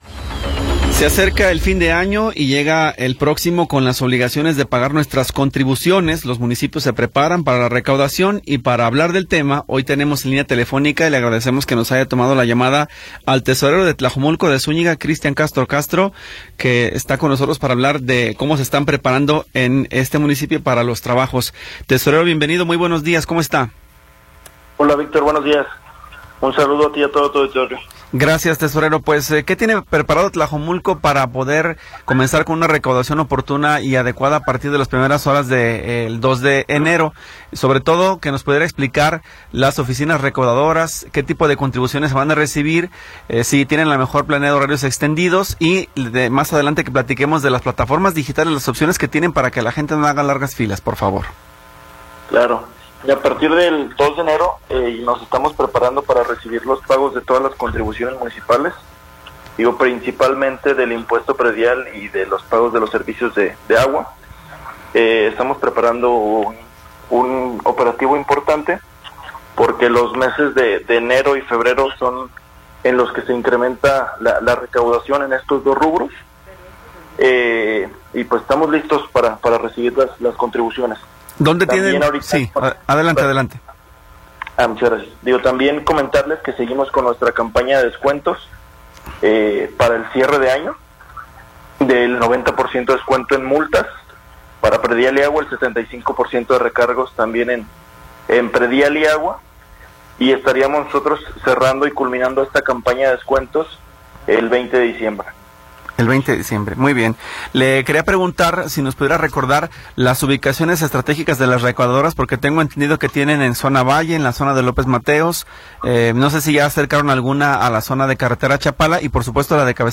Entrevista con Christian Castro Castro